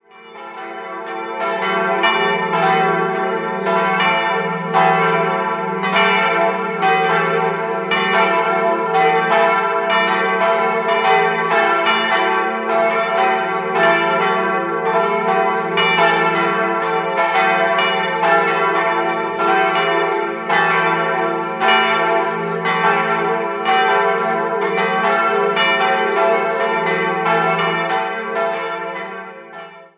3-stimmiges Gloria-Geläute: e'-fis'-a'
e' 900 kg 1961
Bruder-Konrad-Glocke fis' 600 kg 1954
a' 350 kg 1961
bell